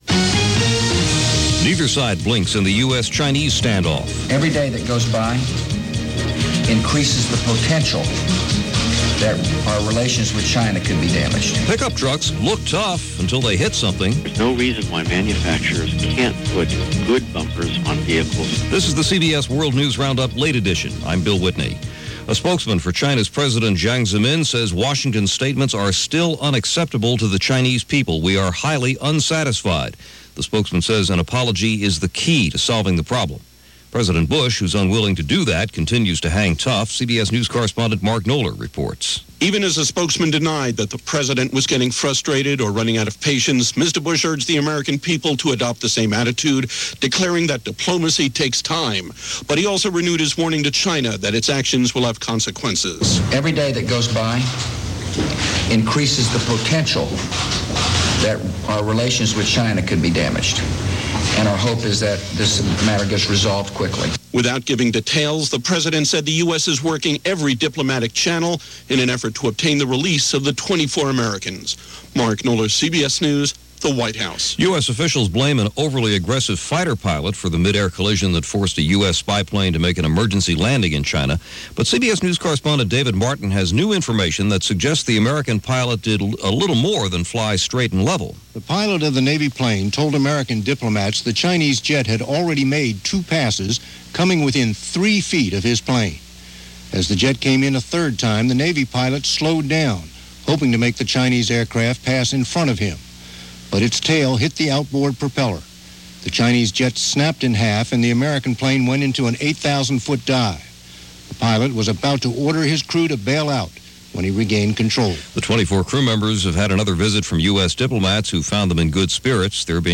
And that’s just a small slice of the news for this April 9, 2001 as presented by The CBS World News Roundup.